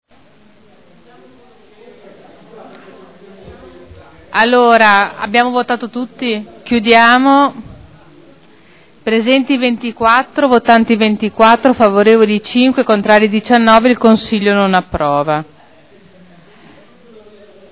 Seduta del 18/04/2011.